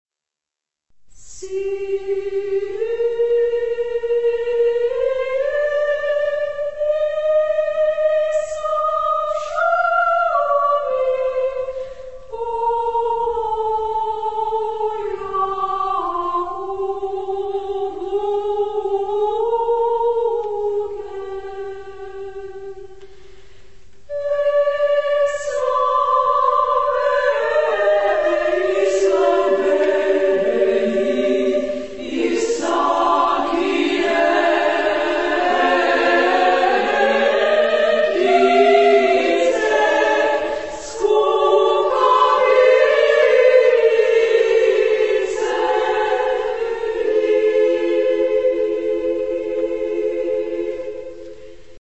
Genre-Style-Forme : Profane ; contemporain ; Madrigal
Caractère de la pièce : mélancolique
Type de choeur : SMA  (3 voix égales de femmes )
Tonalité : Second mode de Messiaen